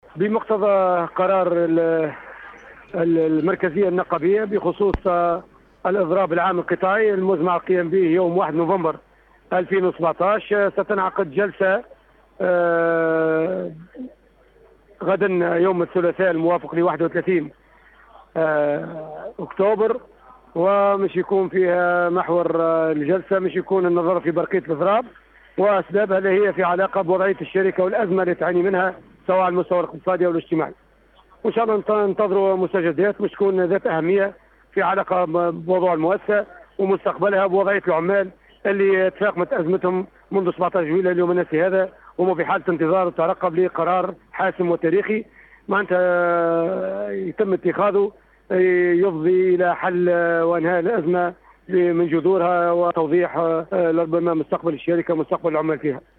تصريح خاص